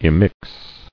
[im·mix]